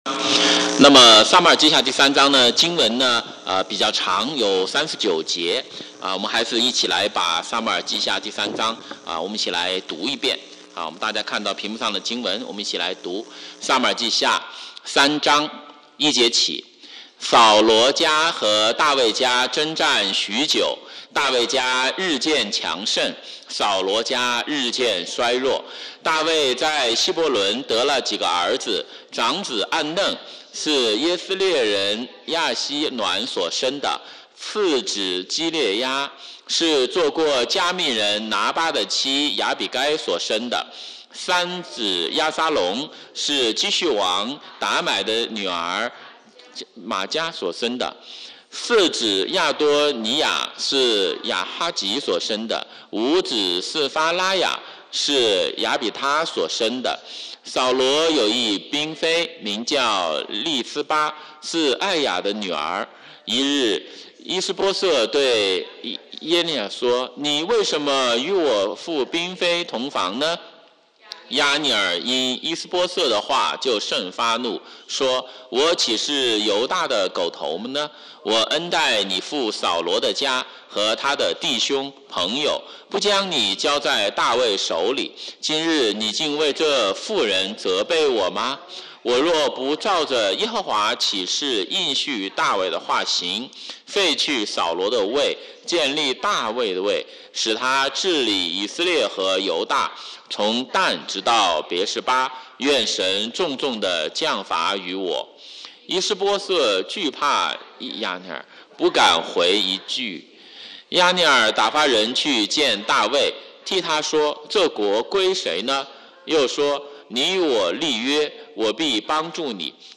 周五晚上查经讲道录音